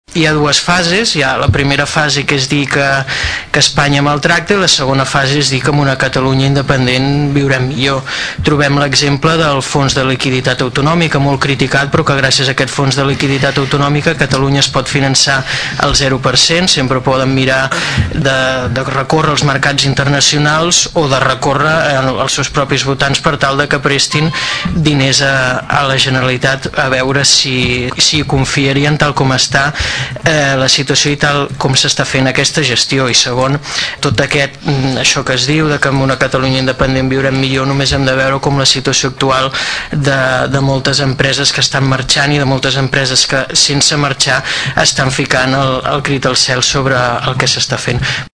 El regidor del PP, Xavier Martín, va apuntar que ell sempre ha estat molt crític amb aquest tipus de mocions, que s’allunyen de l’àmbit estrictament municipal.